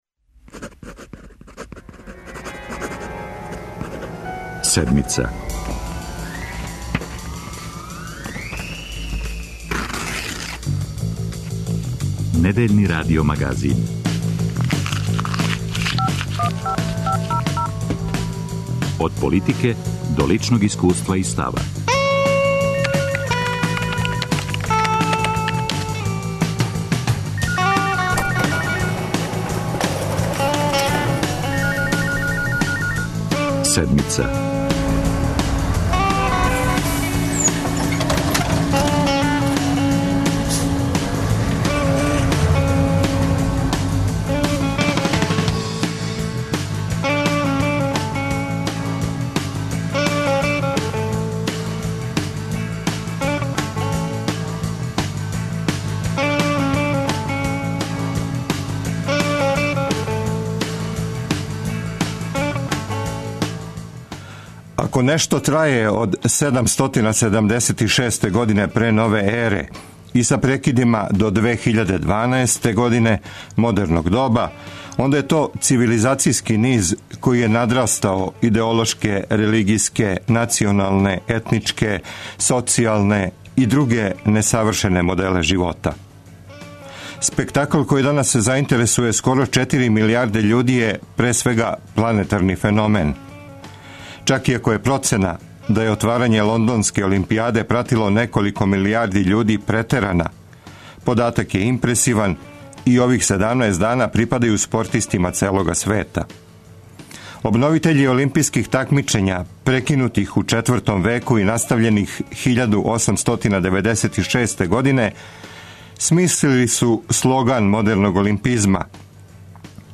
Олимпијада - спорт или више од тога? У емисији говоримо о боји и квадратури олимпијских кругова. У студију Радио Београда 1 биће носиоци олимпијских медаља – спортисти, тренери а и експерти за безбедност.